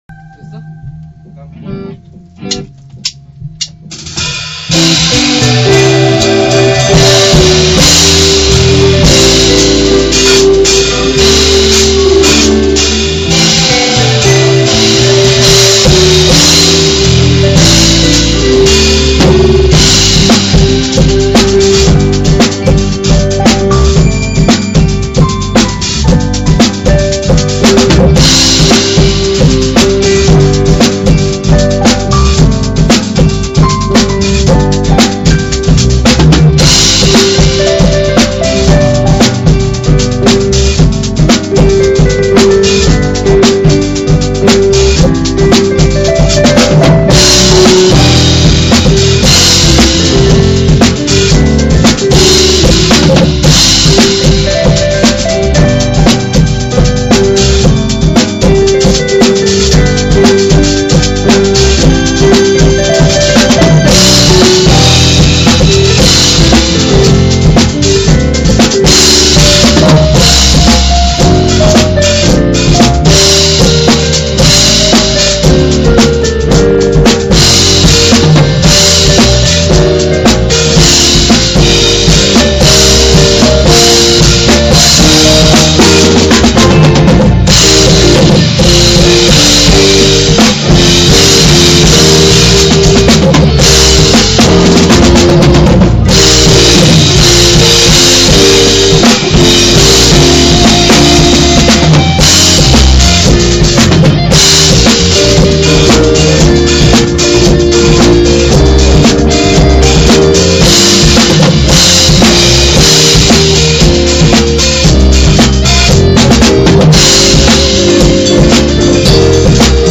2007-09-04 역시 드럼 소리가 중심인것처럼 녹음되었군요.
2007-09-04 오~ 기타로 하니 색다른 느낌!